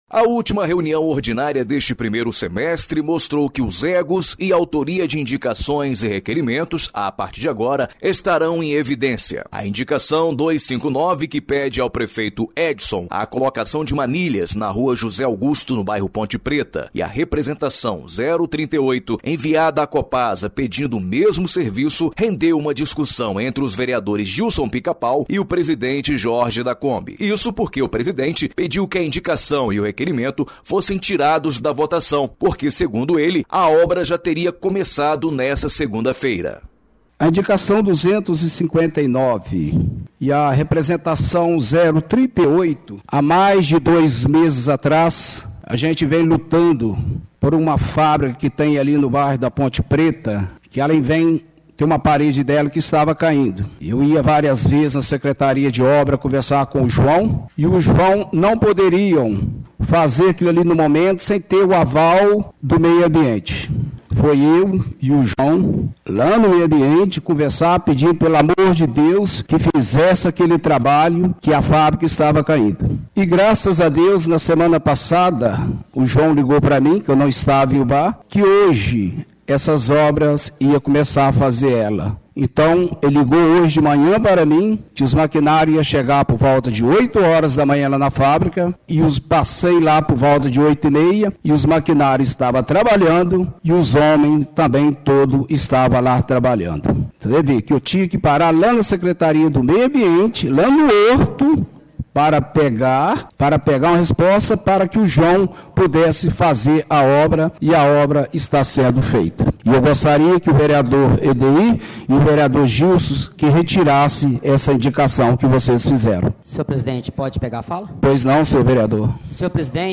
OUÇA O INFORMATIVO CÂMARA EXIBIDO NA RÁDIO EDUCADORA